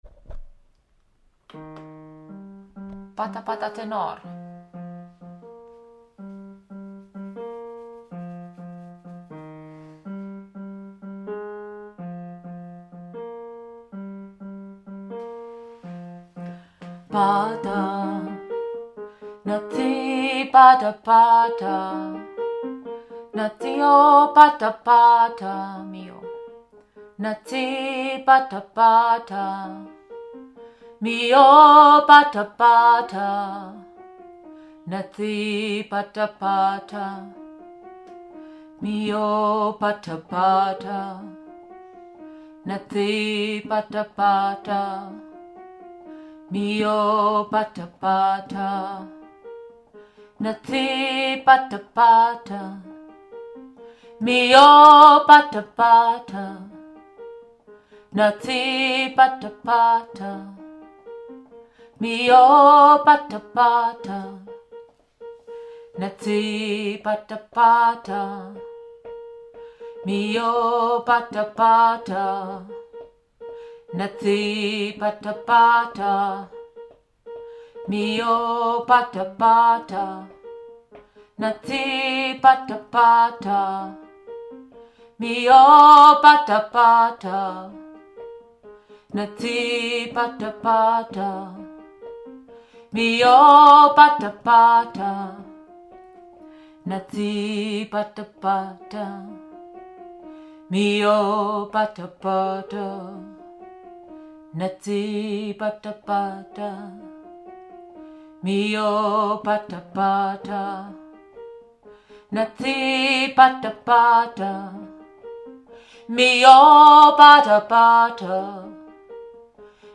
Pata Pata – Tenor
Pata-Pata-Tenor.mp3